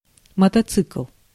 Ääntäminen
Synonyymit motorcycle Ääntäminen UK : IPA : [ˈməʊ.tə.baɪk] Haettu sana löytyi näillä lähdekielillä: englanti Käännös Ääninäyte Substantiivit 1. мотоцикл {m} (mototsikl) Määritelmät Substantiivit (UK) A motorcycle .